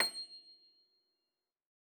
53j-pno26-C6.wav